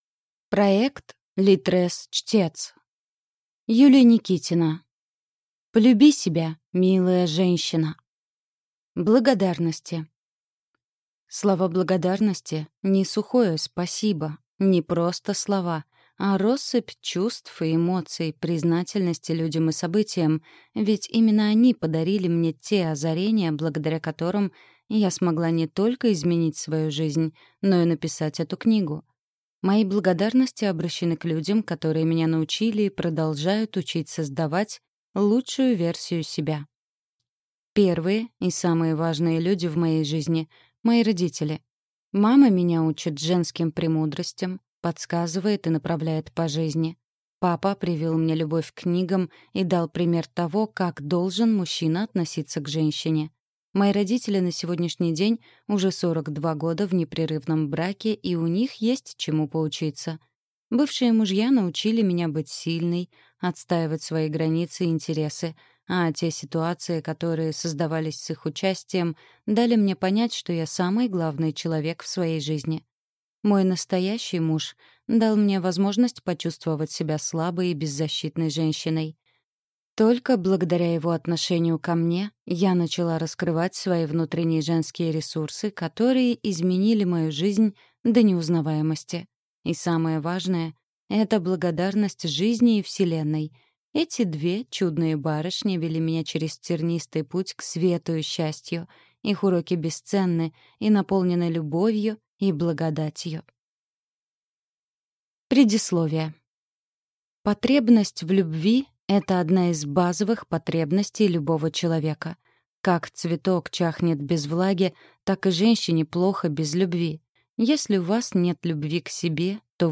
Аудиокнига Полюби себя, милая женщина!